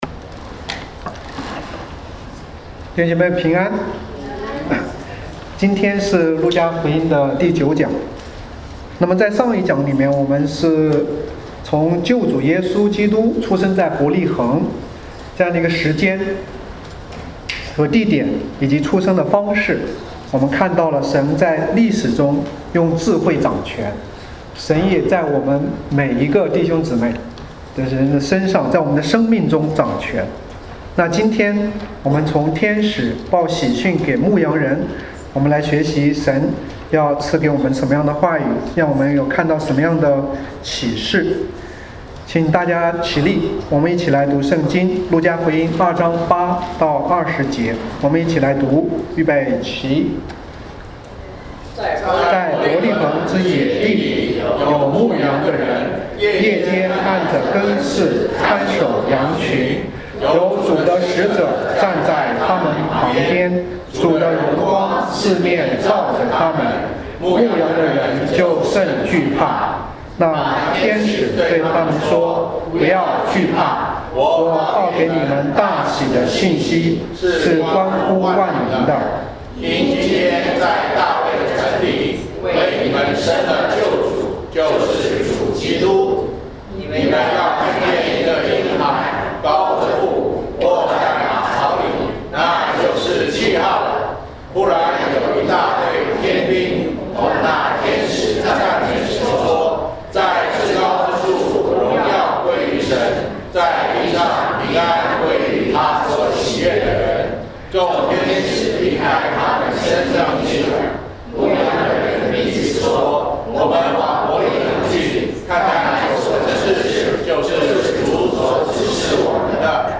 Luke 2:8-20 Service Type: Sunday Service 路加福音2:8-20 8在伯利恆之野地裡有牧羊的人，夜間按著更次看守羊群。